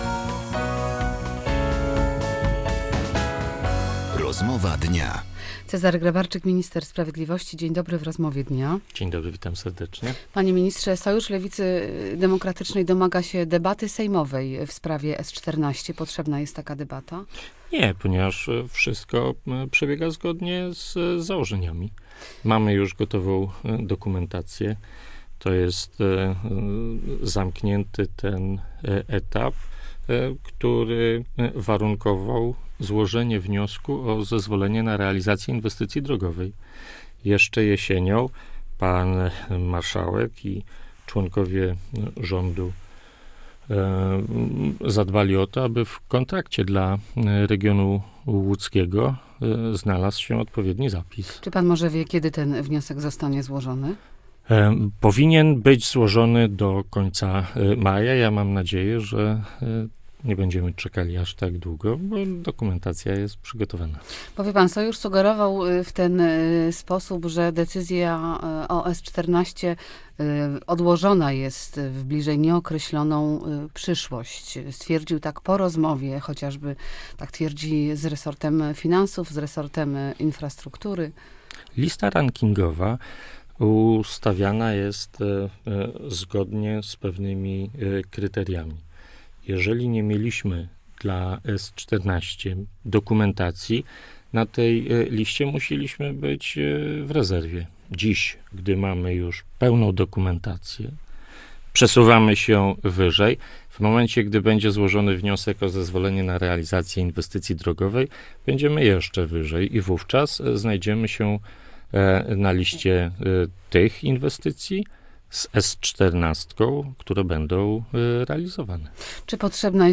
Posłuchaj całej Rozmowy Dnia: Nazwa Plik Autor – brak tytułu – audio (m4a) audio (oga) Warto przeczytać Lionel Richie zaśpiewał w łódzkiej Atlas Arenie.